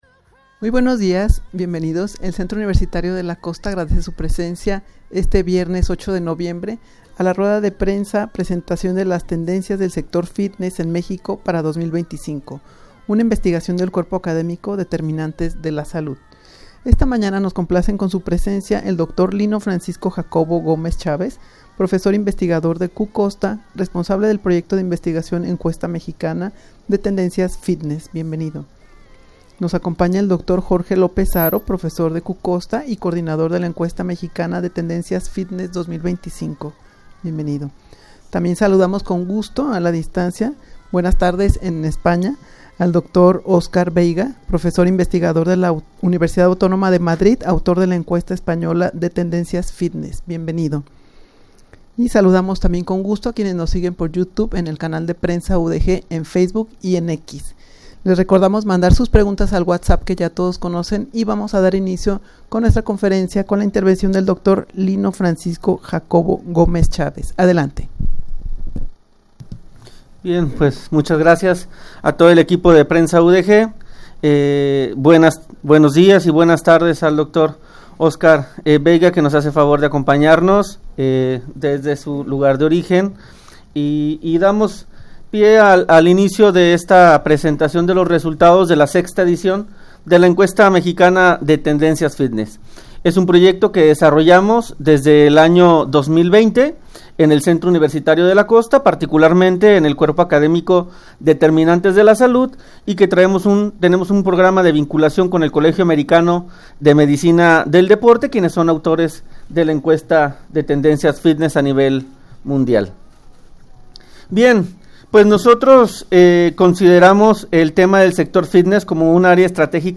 Audio de la Rueda de Prensa
rueda-de-prensa-presentacion-de-las-tendencias-del-sector-fitness-en-mexico-para-2025.mp3